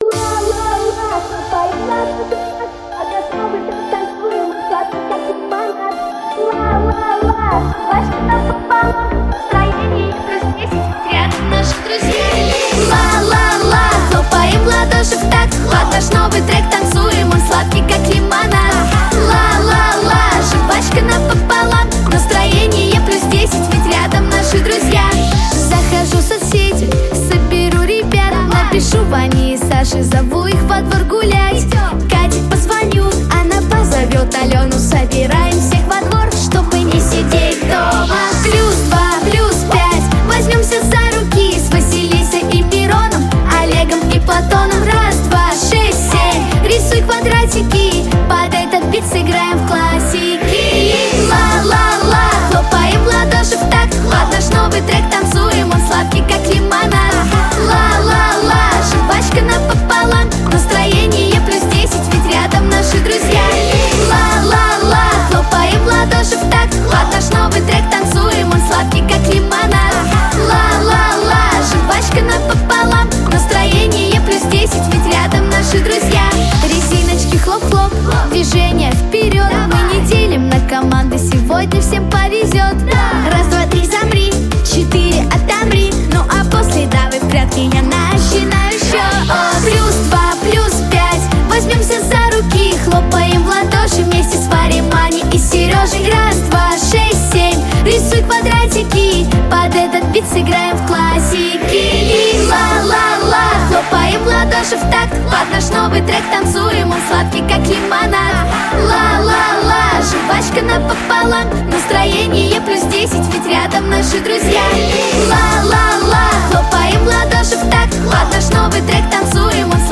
• Качество: Хорошее
• Жанр: Детские песни
детская дискотека, танцевальная
Детская музыкальная группа